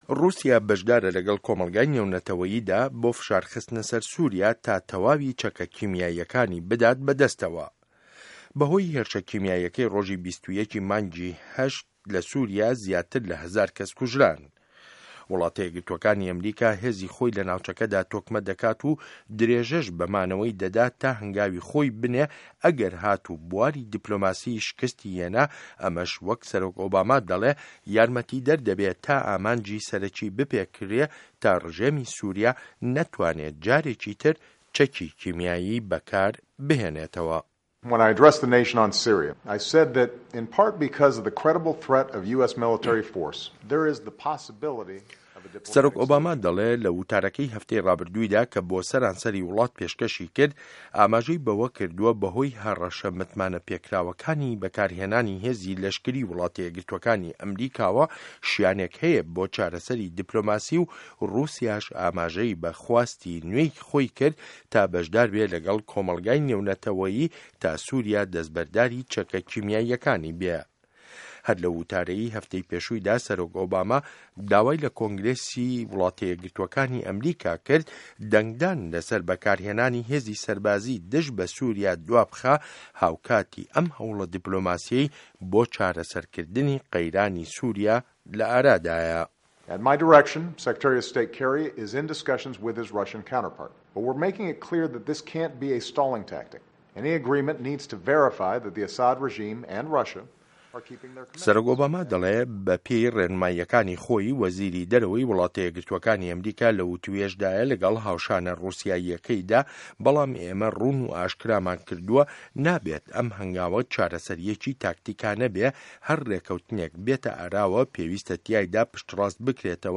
ڕاپۆرت له‌سه‌ر وتاری هه‌فتانه‌ی سه‌رۆک ئۆباما